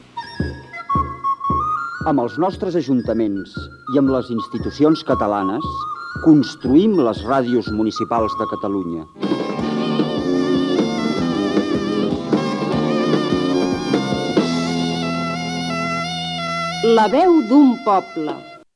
Promoció "La veu d'un poble"
FM